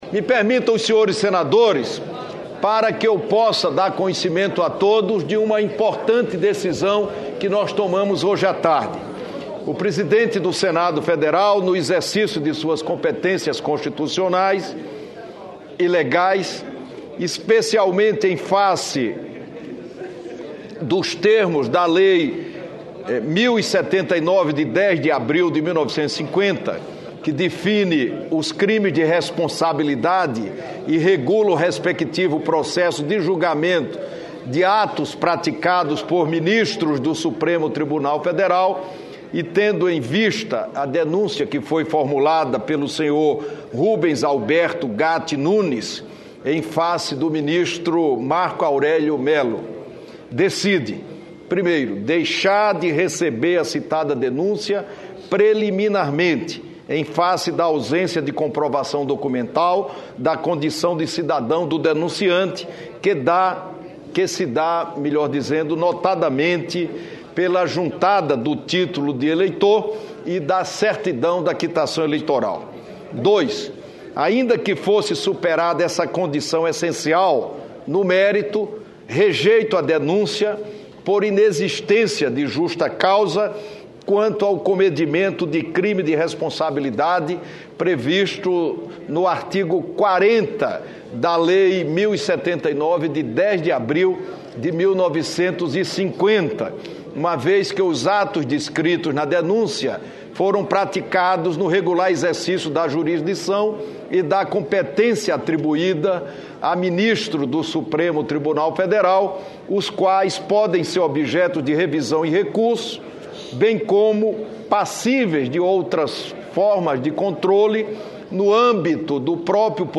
Discursos
Acompanhe o áudio com as palavras do presidente.